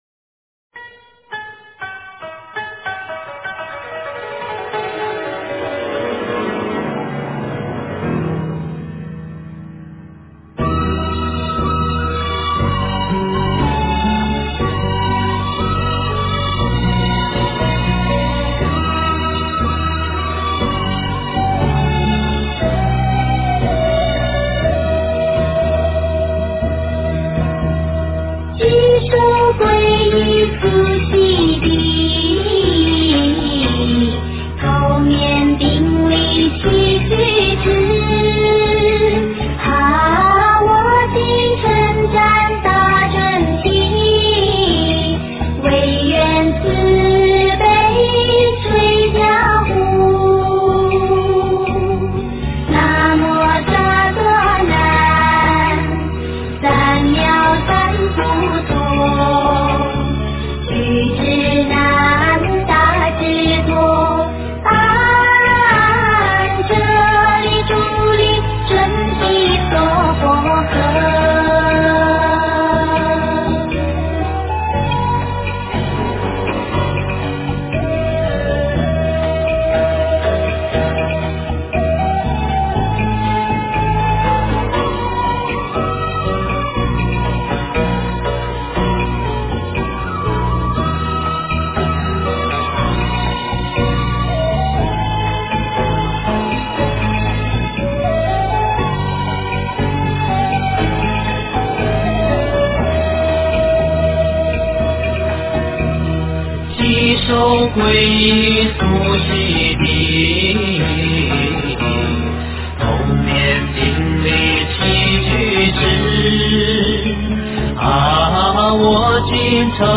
佛母准提神咒--佛音 真言 佛母准提神咒--佛音 点我： 标签: 佛音 真言 佛教音乐 返回列表 上一篇： 南无护法韦驮尊天菩萨--无名氏 下一篇： 般若心经咒--佚名 相关文章 楞伽阿跋多罗宝经4 楞伽阿跋多罗宝经4--未知...